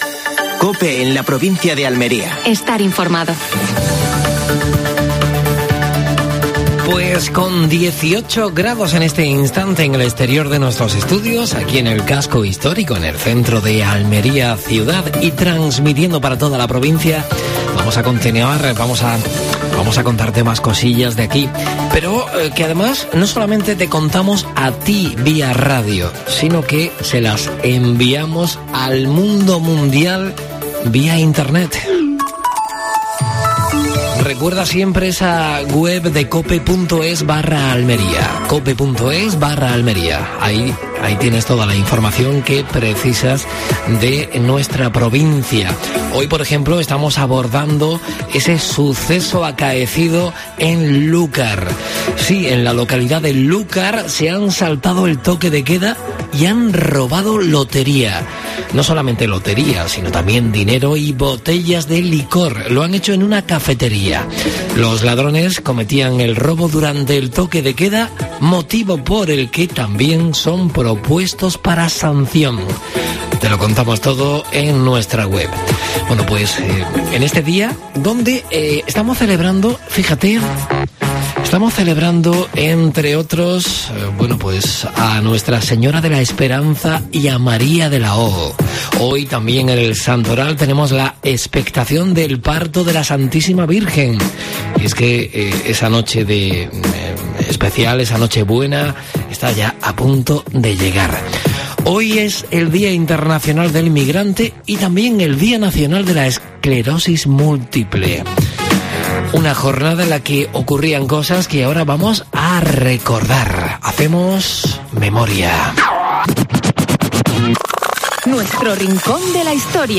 AUDIO: Actualidad en Almería. Entrevista a Carlos Sánchez (concejal del Ayuntamiento de Almería). La UDA jugará en Soria en Copa del Rey.